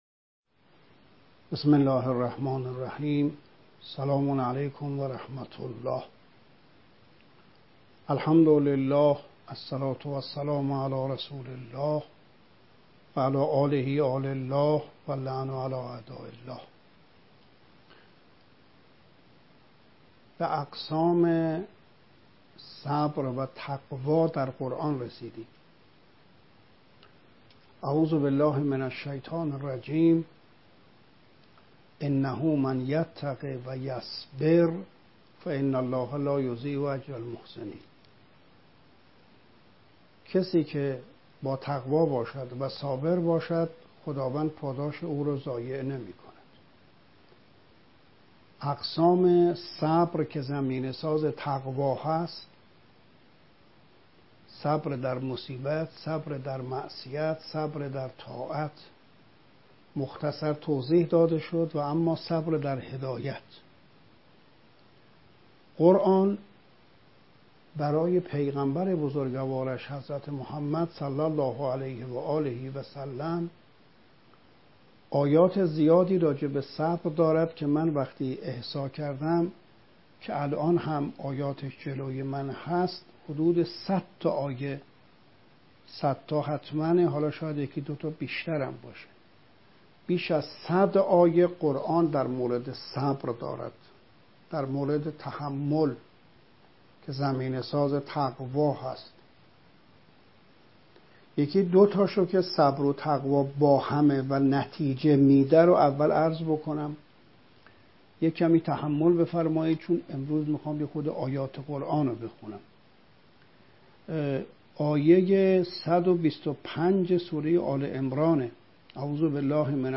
استخراج صدا - اقسام صبر